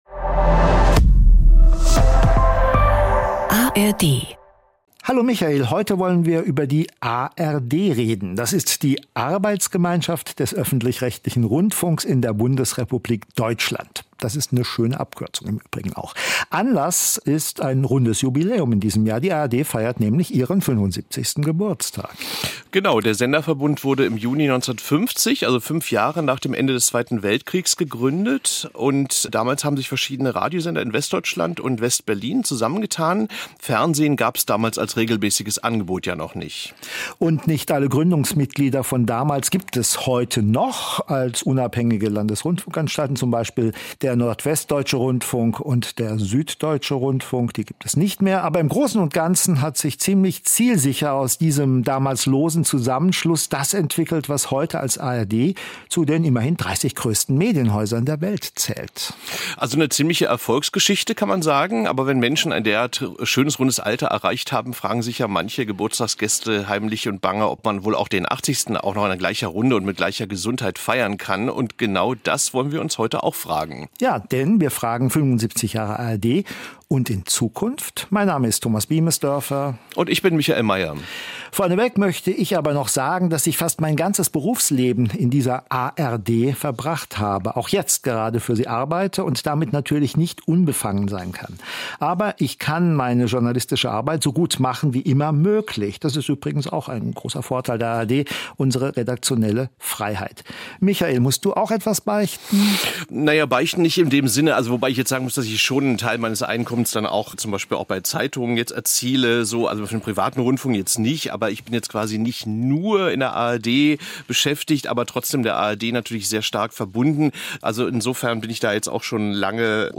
Die Moderatorinnen und Moderatoren informieren, diskutieren und räsonieren jede Woche über neue Trends und kontroverse Themen aus der großen weiten Welt der Medien. Immer zu zweit, immer voller Meinungsfreude und immer auch mit fundierten und gründlich recherchierten Fakten. Gespräche mit Experten und Expertinnen, Umfragen unter Nutzerinnen und Nutzern und Statements aus der Medienbranche können Probleme benennen und Argumente untermauern.